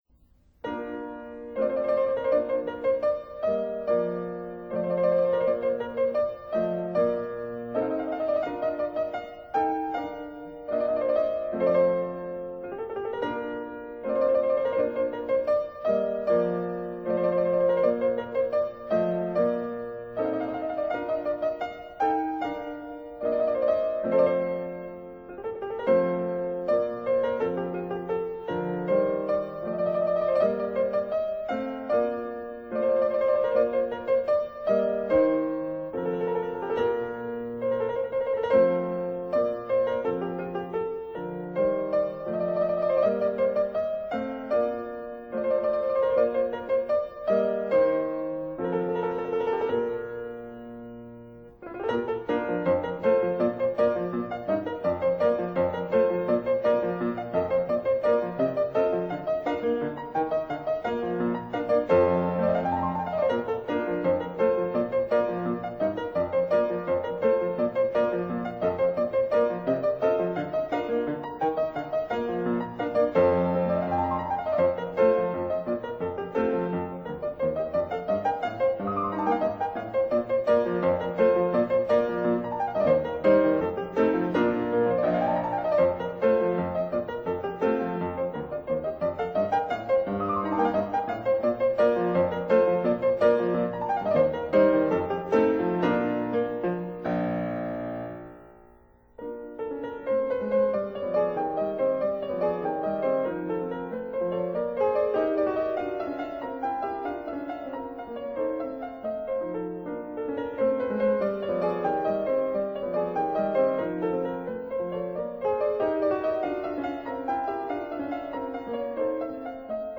recorded April 1983